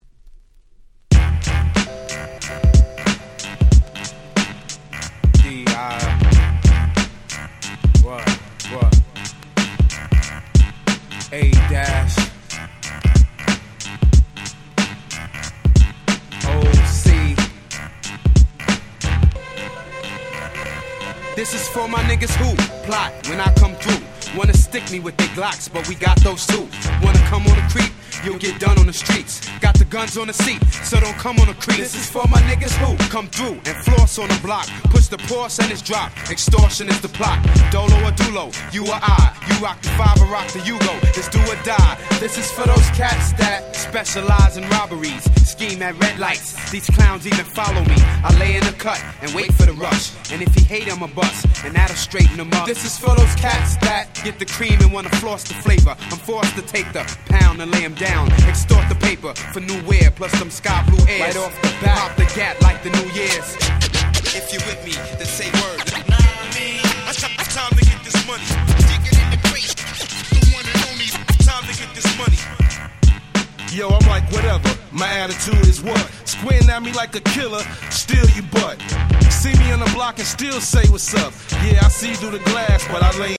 99’ Smash Hit Hip Hop !!
Boom Bap